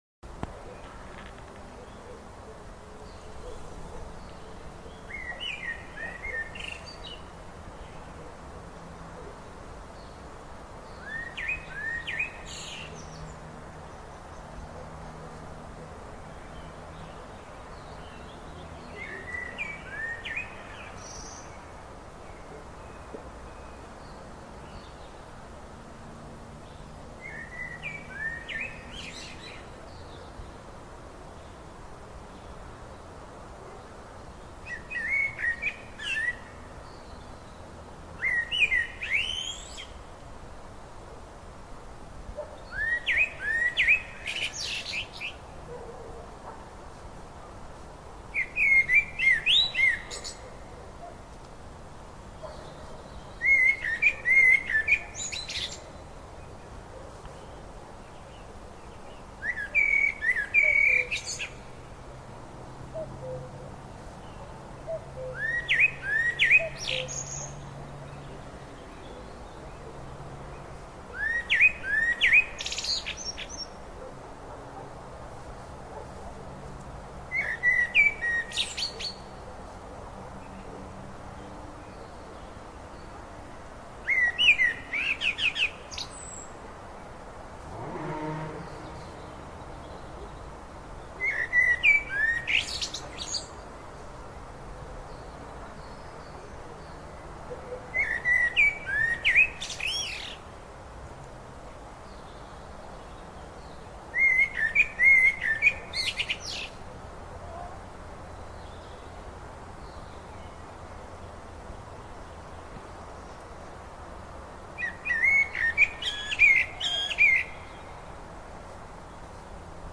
KCEoxvchZkP_Sonidos-del-bosque-mp3.mp3